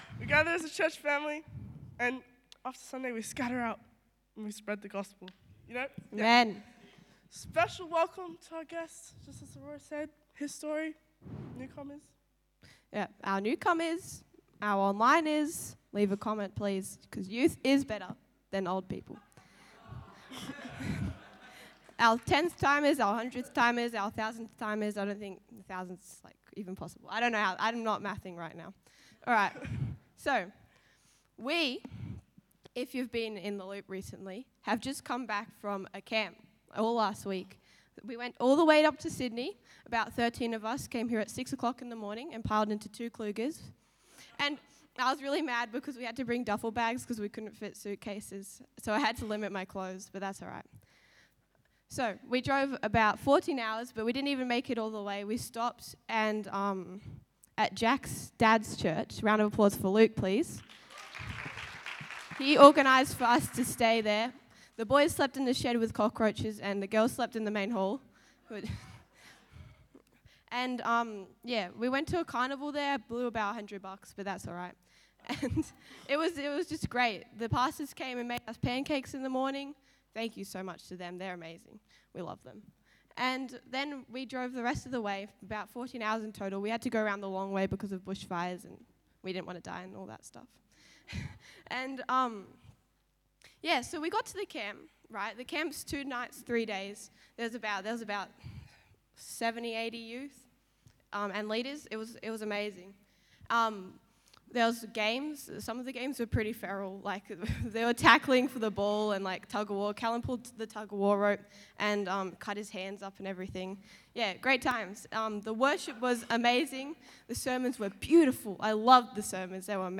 The youth takeover this Sunday and share their highlights of both of the camps that took place over the past week. Encounters, freedom and moments of pure relationship with God was shared this Sunday!